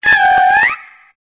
Cri de Tutafeh dans Pokémon Épée et Bouclier.